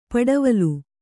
♪ paḍavalu